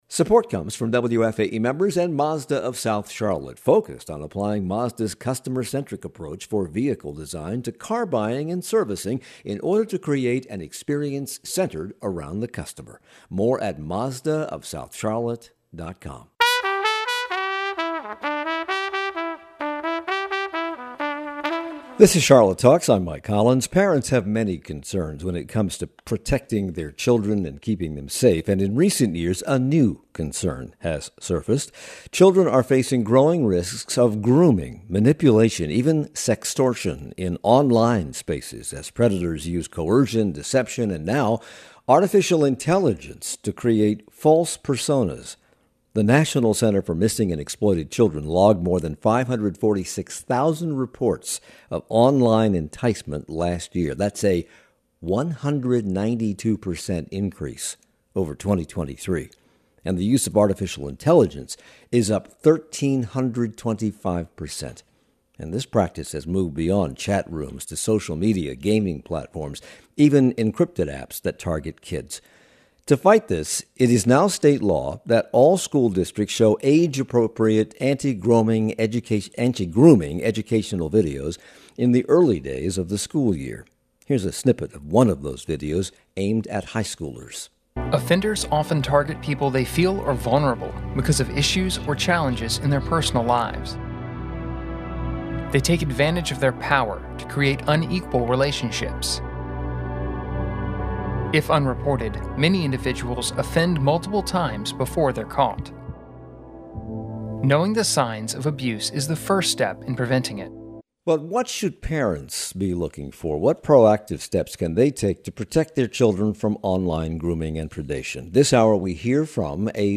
This school year, districts are helping students spot this dangerous trend. We hear from a survivor of online grooming and those working to prevent it.